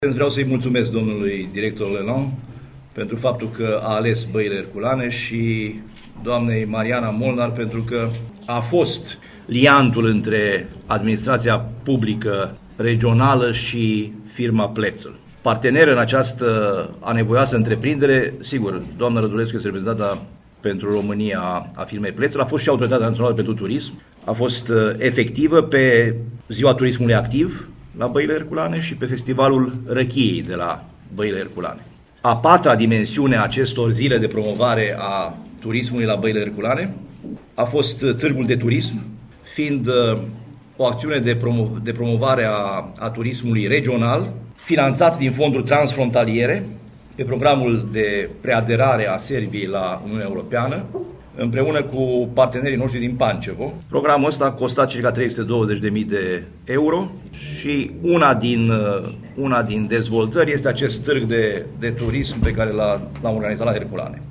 În staţiunea Băile Herculane a avut loc astăzi conferinţa de presă susţinută de organizatorii evenimentelor care se desfăşoară la acest sfârşit de săptămână pe Valea Cernei.
Ascultaţi declaraţia preşedintelui Consiliului Judeţean Caraş-Severin, Sorin Frunzăverde:
Conferinţă-presă-Sorin-Frunzăverde.mp3